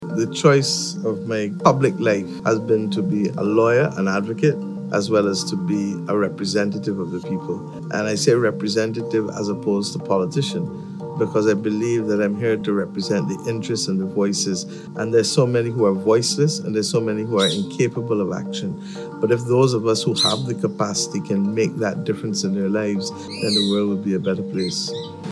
In an interview posted on the Time Magazine website, Miss Mottley speaks of her determination to be a true representative of the people and to give a voice to their concerns.